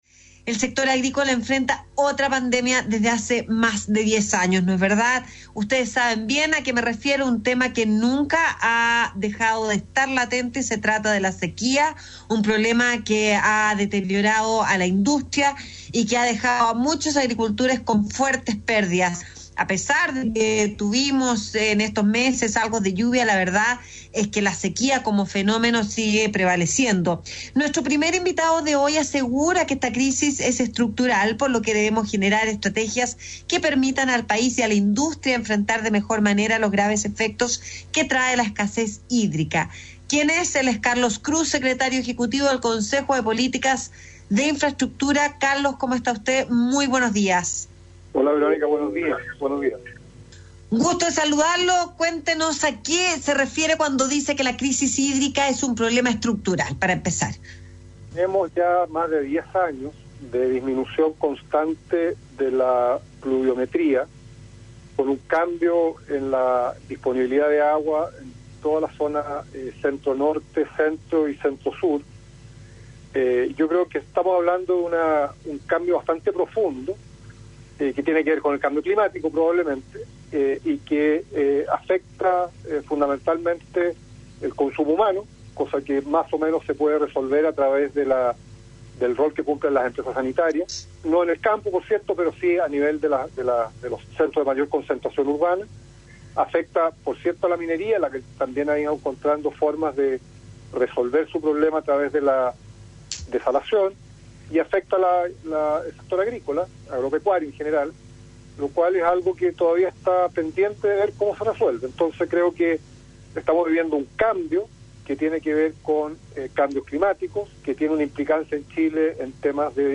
RADIO AGRICULTURA – Audio de entrevista en programa El Agro de Radio Agricultura tocando el tema de la sequía